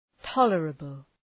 {‘tɒlərəbəl}